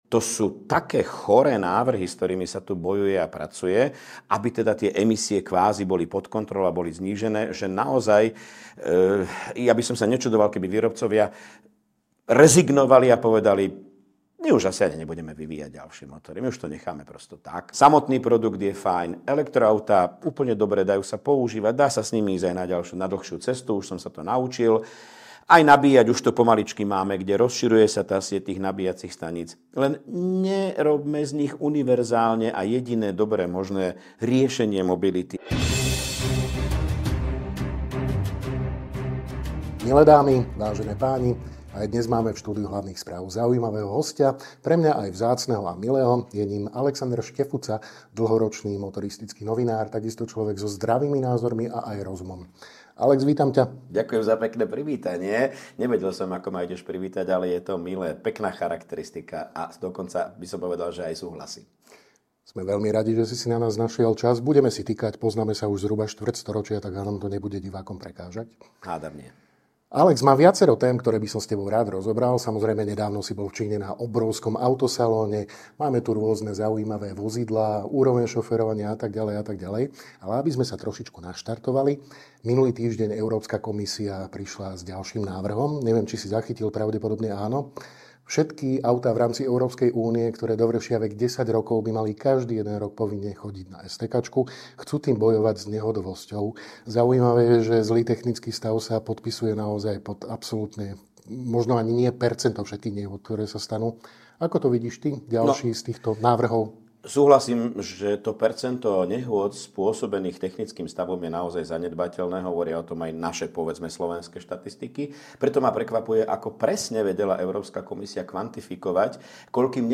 Rozprávali sme sa s motoristickým novinárom, Mgr.